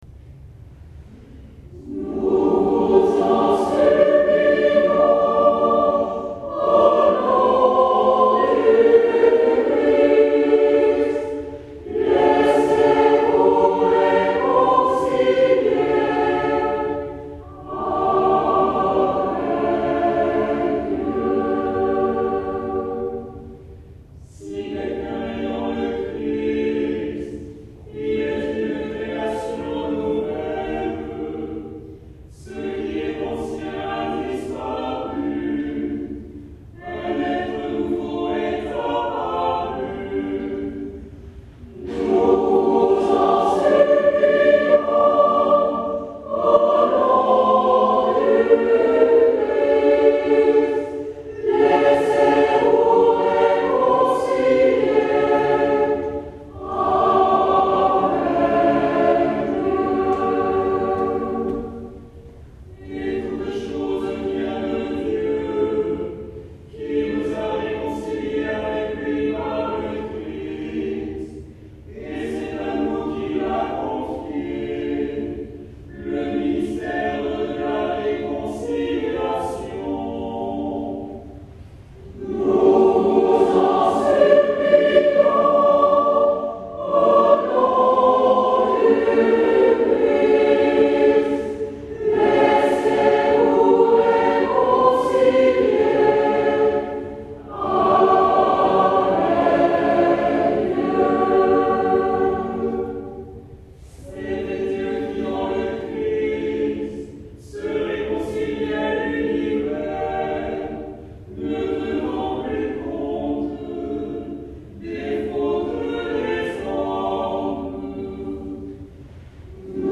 Concerts spirituels
(Veuillez excuser les défauts d'une prise de son amateur du concert)
Concert à Jouy-en-Josas, 12 novembre 2006